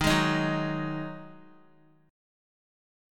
Ebmbb5 chord